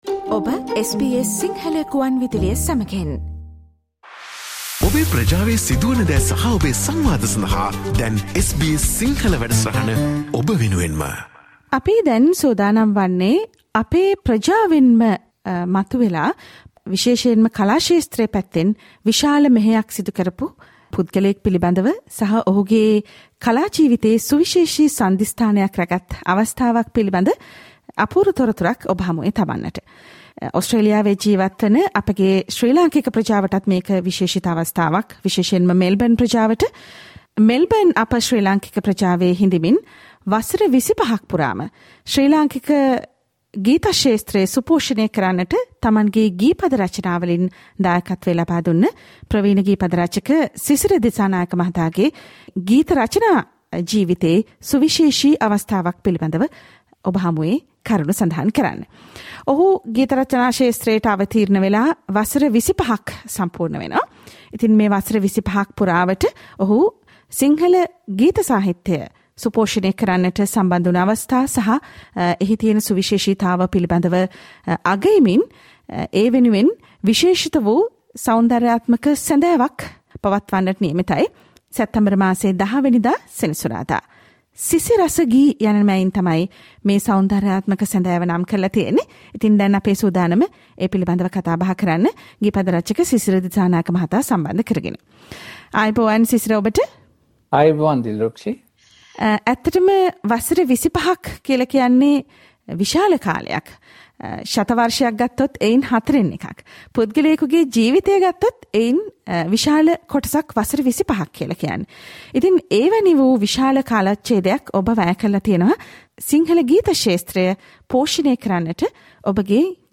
සංගීතමය සන්ධ්‍යාව පිළිබඳව SBS සිංහල සේවය සමඟ සිදුකල පිළිසඳරට සවන්දෙන්න....